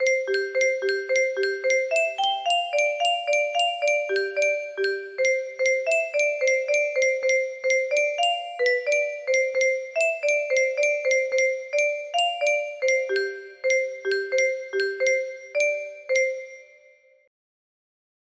A Little Night Music Mozart. music box melody